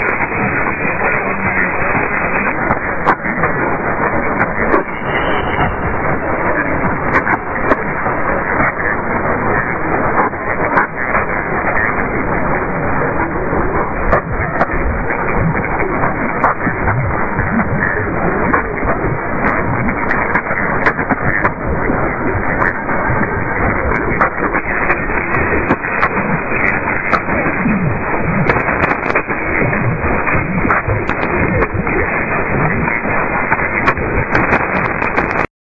Per la serie "poche ma buone" (e soprattutto rare), riporto alcuni incontri significativi di questi ultimi due giorni e qualche clip (solito consiglio di usare le cuffie per ascoltarli meglio).